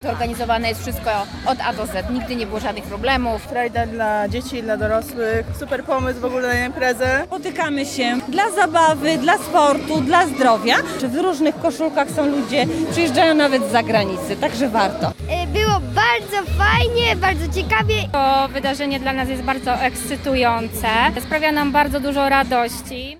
Posłuchaj relacji: Nazwa Plik Autor Ice Cream Run 2022.